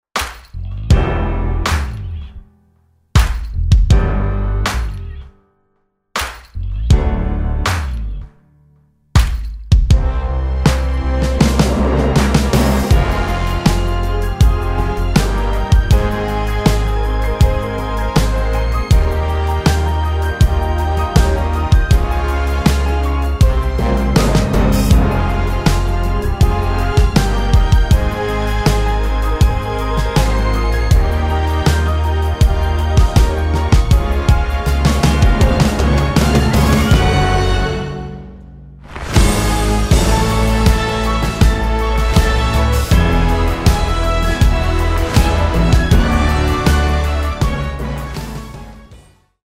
orchestral performance track
Instrumental